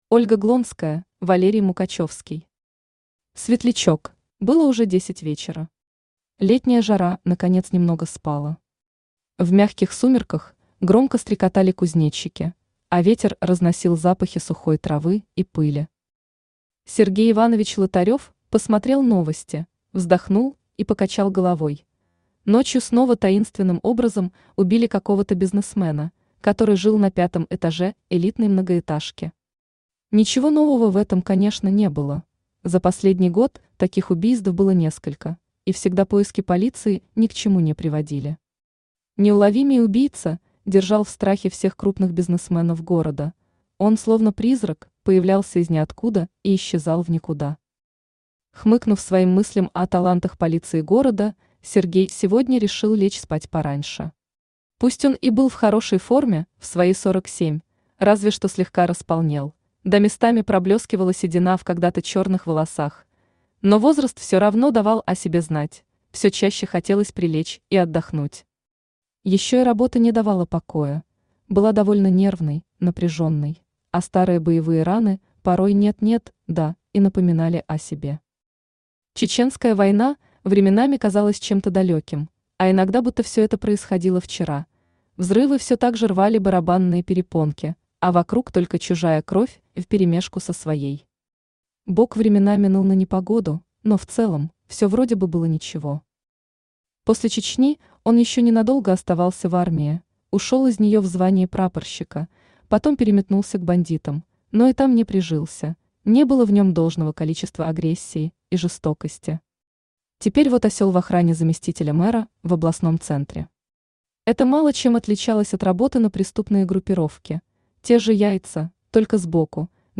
Аудиокнига Светлячок | Библиотека аудиокниг
Aудиокнига Светлячок Автор Ольга Глонская Читает аудиокнигу Авточтец ЛитРес.